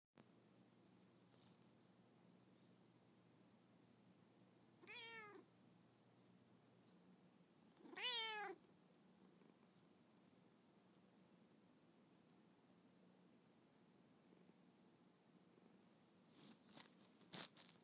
My kittycat with cataracts has a lovely voice.
But for a start, hereʻs a tiny "meow" from Ernie.